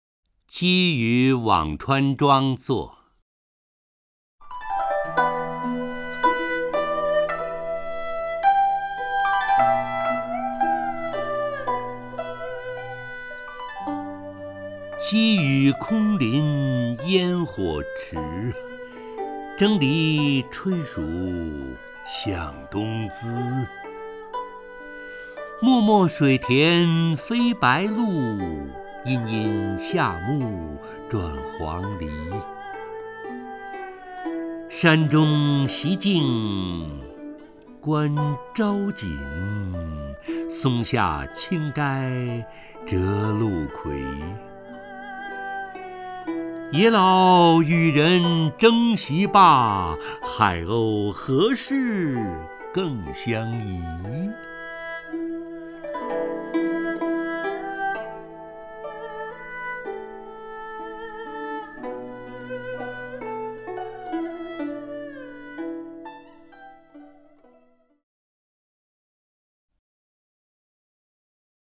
陈醇朗诵：《积雨辋川庄作》(（唐）王维)
名家朗诵欣赏 陈醇 目录